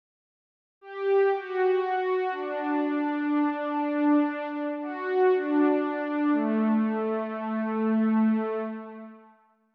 Default Oscillating string sound ...
(Original Preset)
I think your sounds are too soft and rounded. it's definitely more a saw lead, with more reverb then phase. try a simple string sounds, feed it through some hall delay, and crank some of those newer, juicy, distortions for the edge.